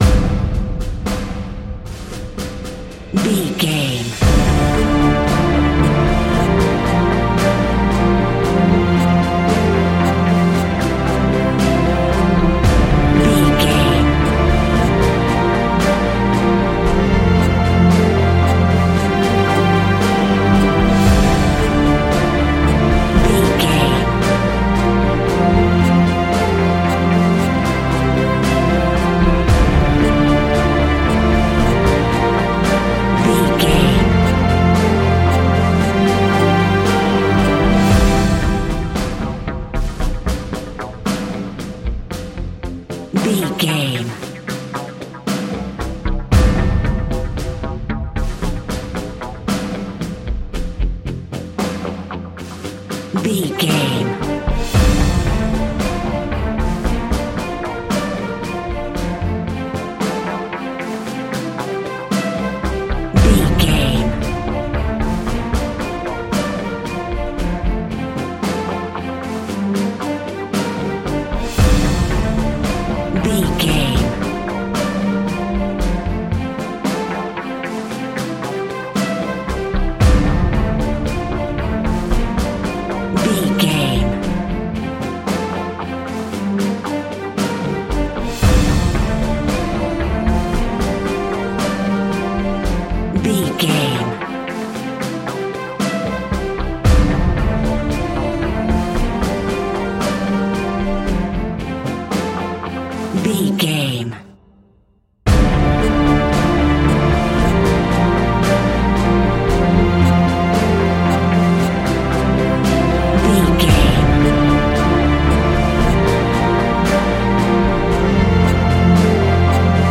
Aeolian/Minor
D
ominous
driving
powerful
strings
brass
percussion
cinematic
orchestral
taiko drums
timpani